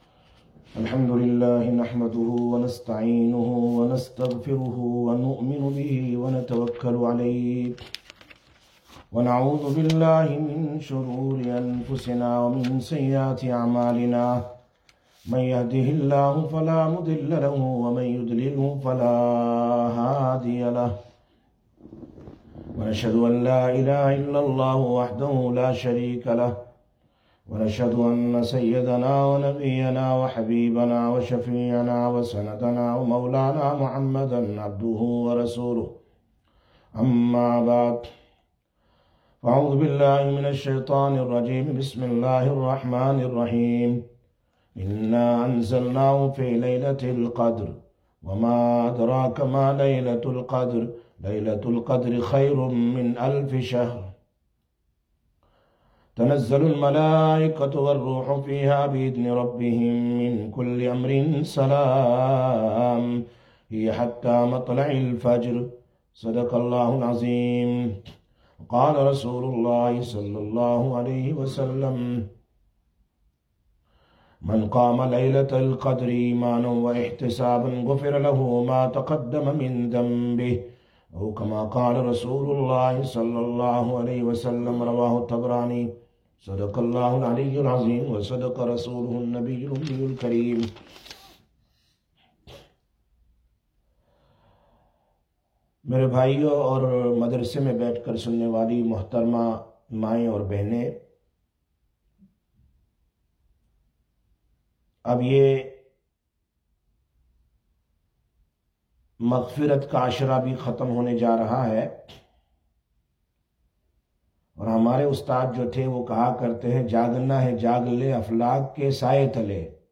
20/04/2022 Sisters Bayan, Masjid Quba